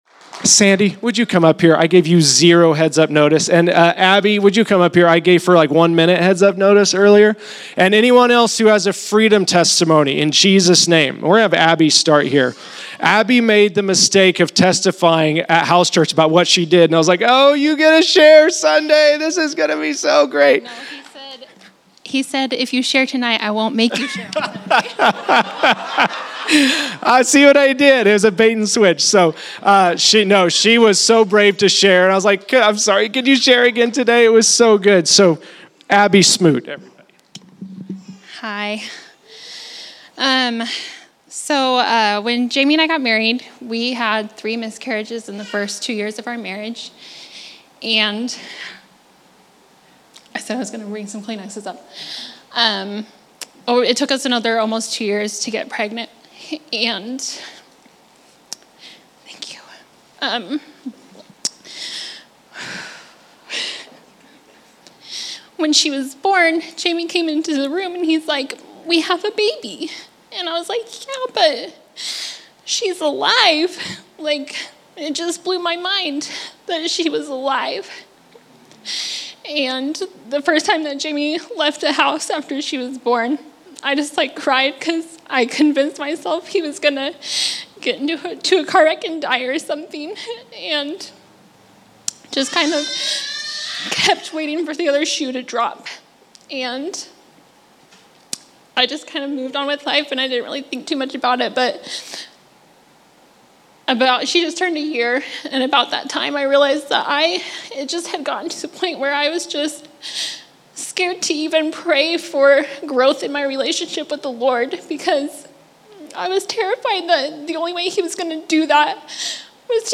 August 28, 2022      |     By: El Dorado Equip      |      Category: Testimonies      |      Location: El Dorado